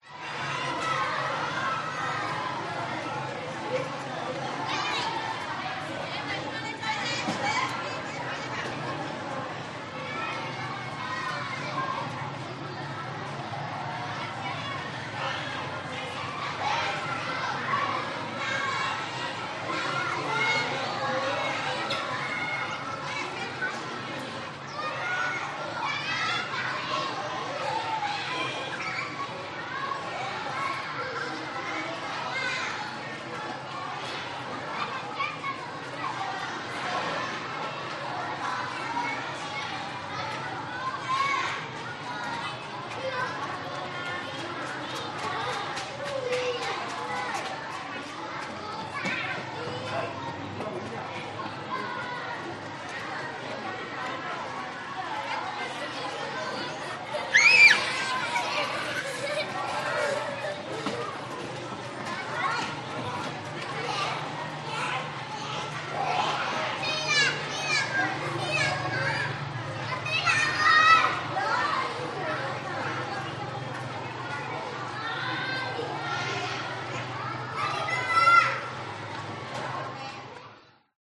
Шум вьетнамской речи, звуки живого двора и смех играющих детей